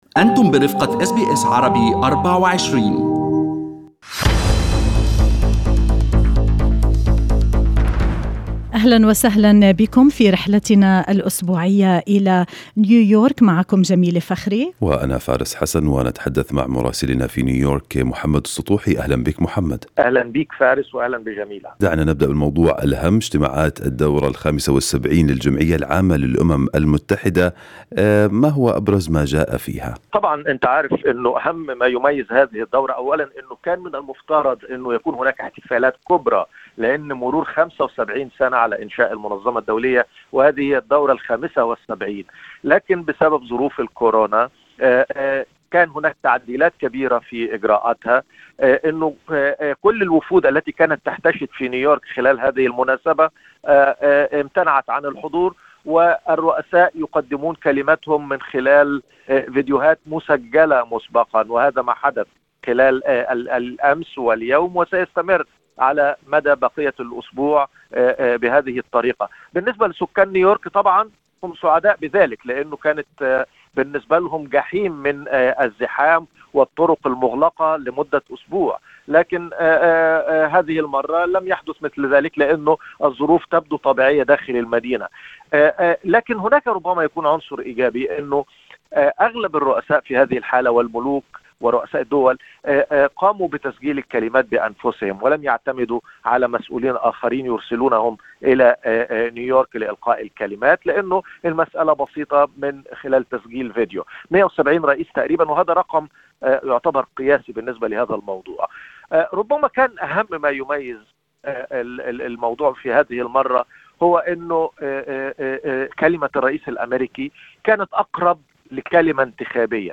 من مراسلينا: أخبار الولايات المتحدة الأمريكية في أسبوع 24/9/2020